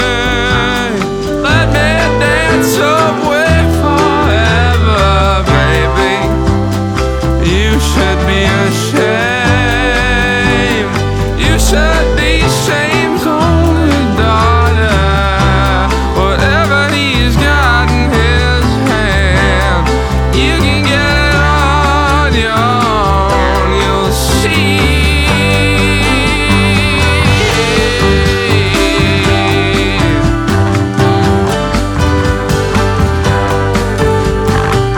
2025-08-19 Жанр: Альтернатива Длительность